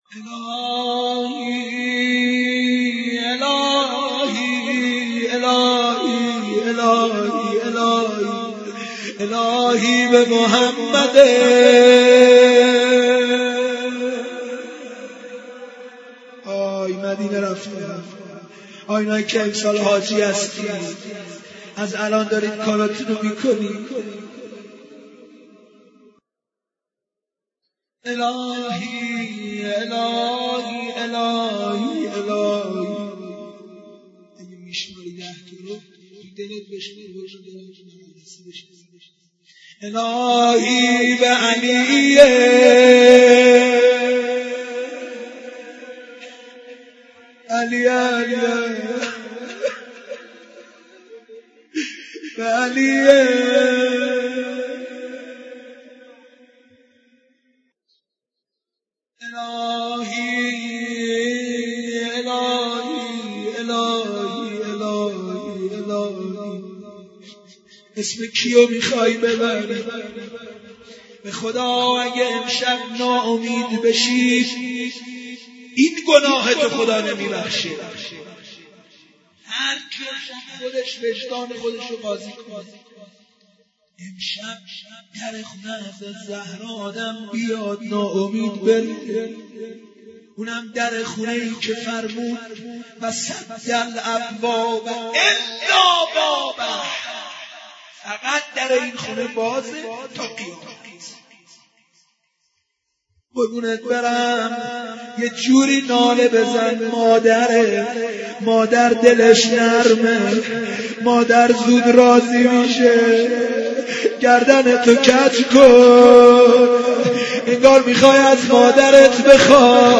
مراسم بک یا الله در شب قدر